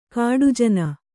♪ kāḍu jana